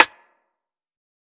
TC3Snare8.wav